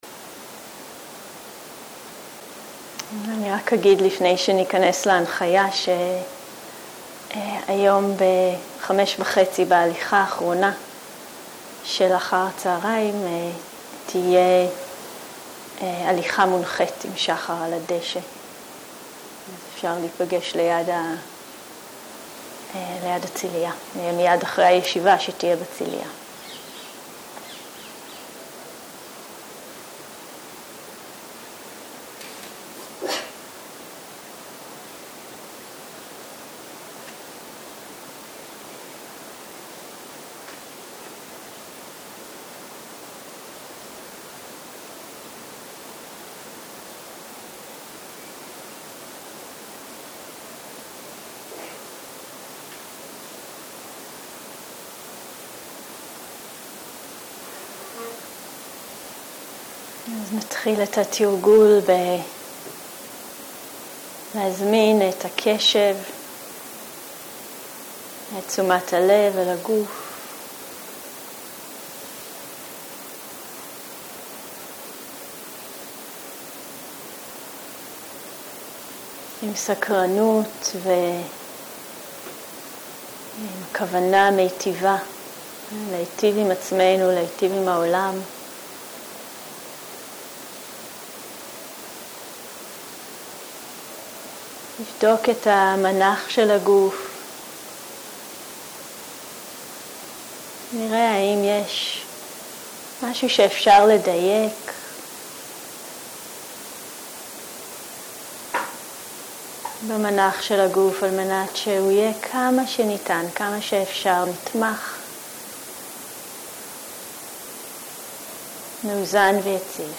Guided meditation